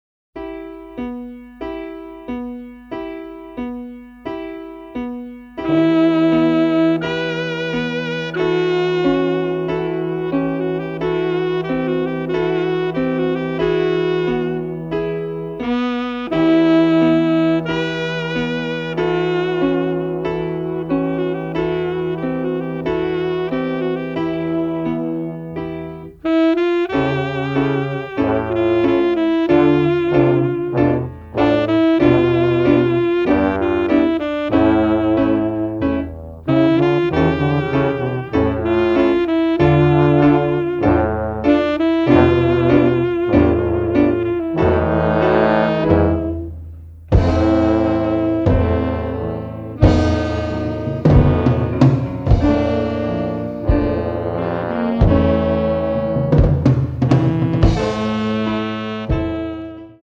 これまでカセットテープのみでの販売となっておりましたが、デジタルリマスターを施した形でのＣＤ化となりました！“
全体を貫く葬送歌のような物悲しい叙情性と、ジャズの過激さが同居しているサウンドがとにかく絶品です！